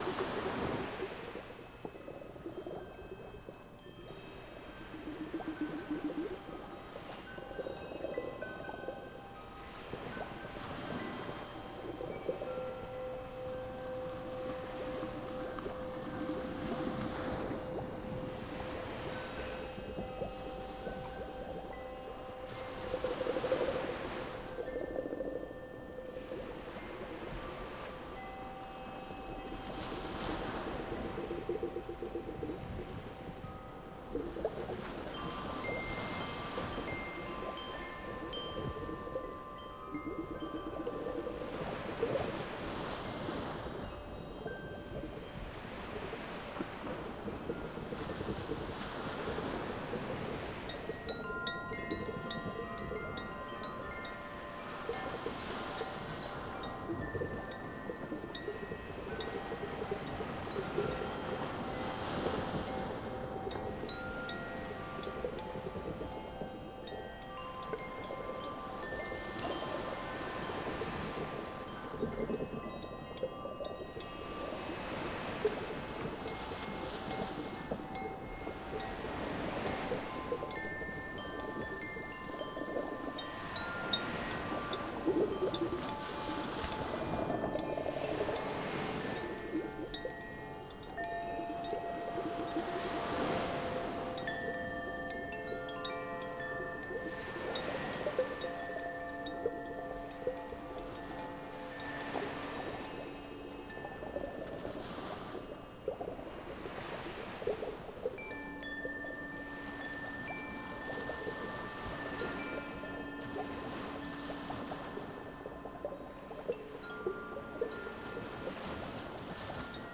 Wind Chimes Water Droplets and Ocean Waves Loop Sound Effect — Free Download | Funny Sound Effects
A combination of my Wind Chimes sounds and Water sounds for the ultimate